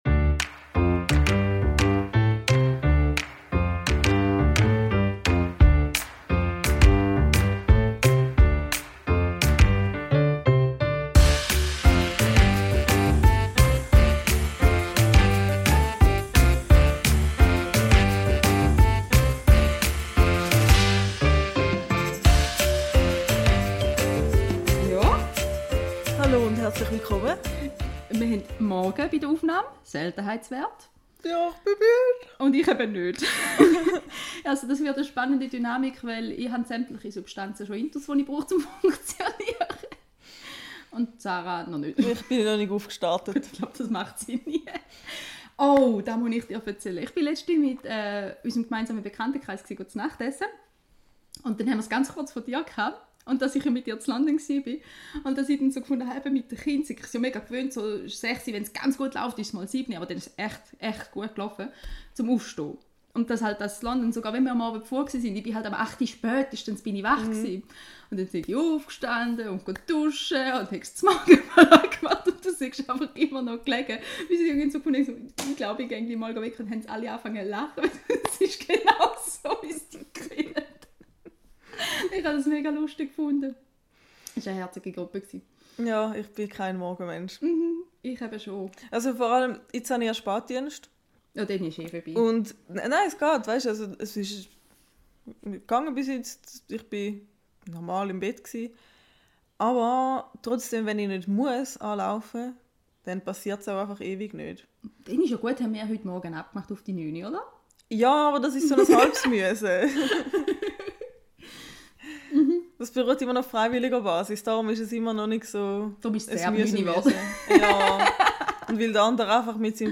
Eine Folge aus dem Homeoffice, sozusagen.